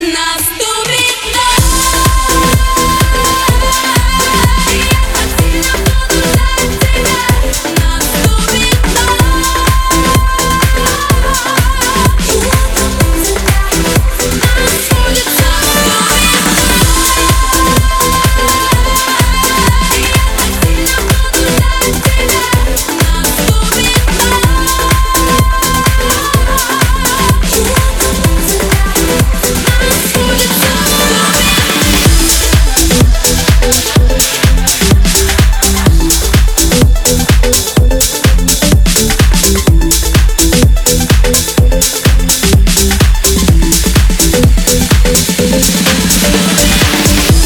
remix
Хип-хоп
dance
electro